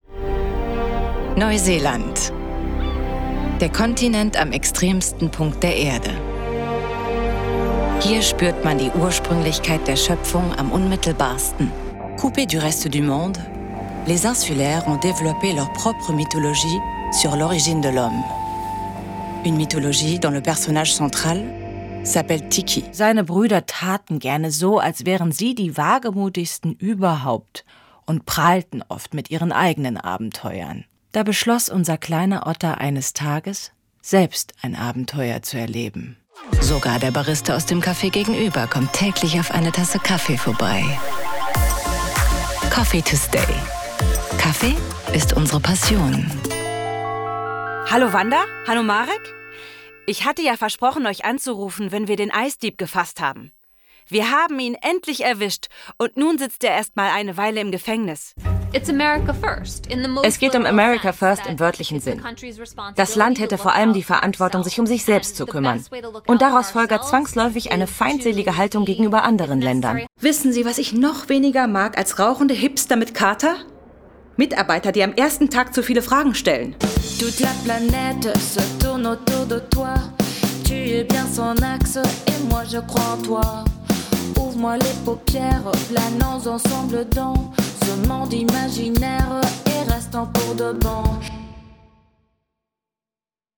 deutsch-französische schauspielerin & sprecherin
Lebendig. Vielseitig. Authentisch.
Voice-Demoreel.wav